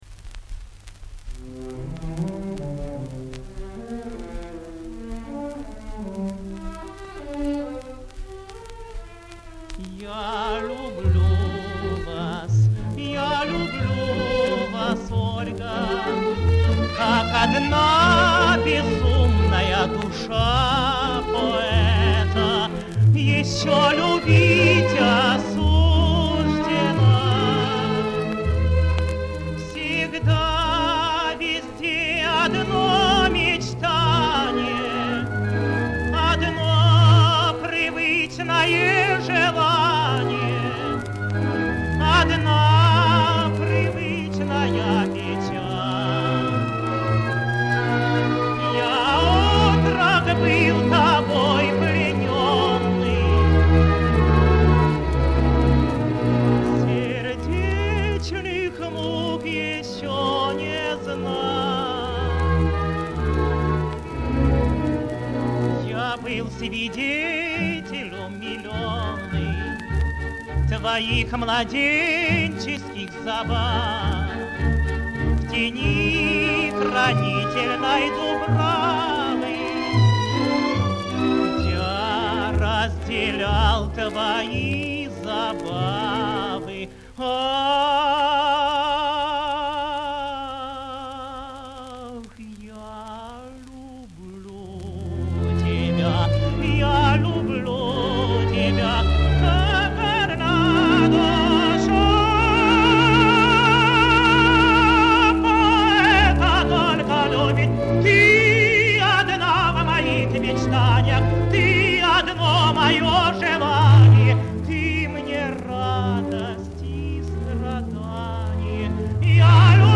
Ариозо Ленского. Оркестр Большого театра. Дирижёр А. Ш. Мелик-Пашаев. Исполняет И. С. Козловский.